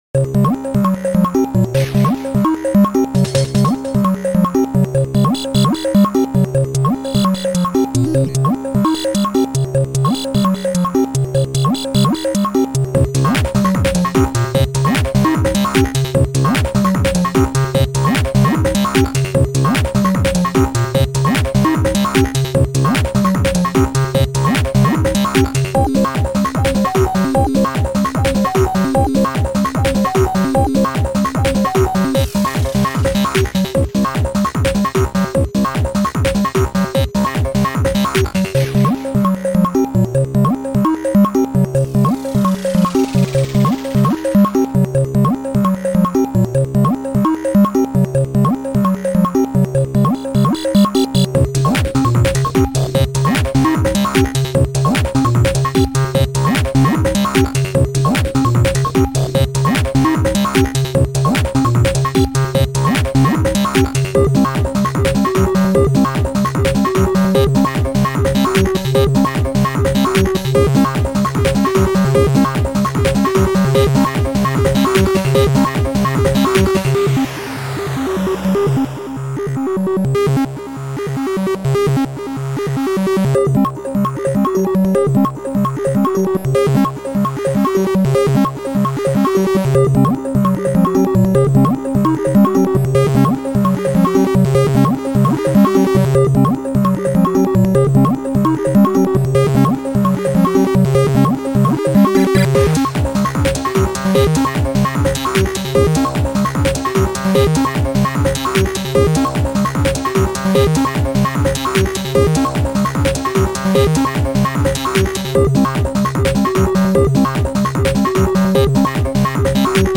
Chip Music Pack